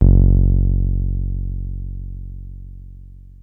303 F#1 6.wav